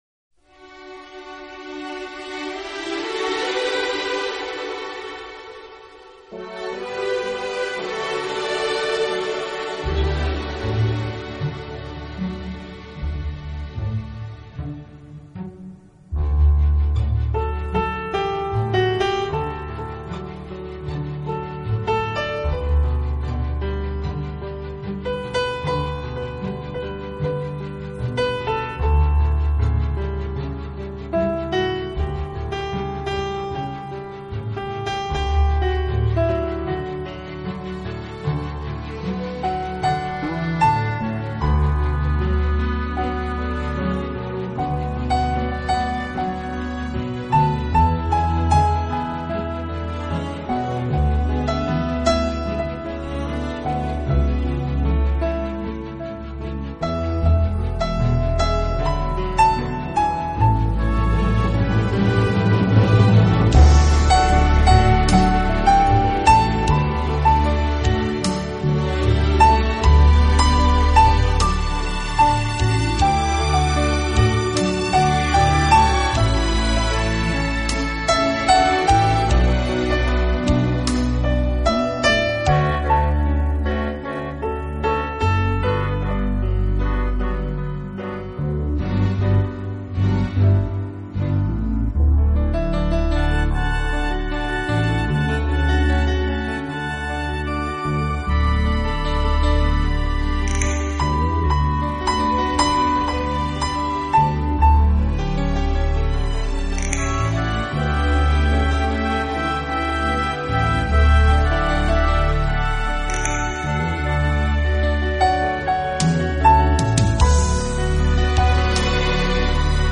【名品钢琴】